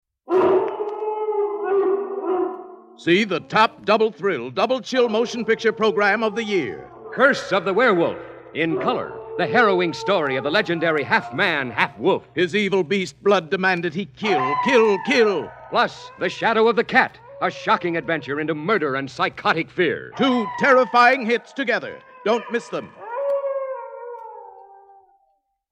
Howl With Some Werewolf Movie Radio Spots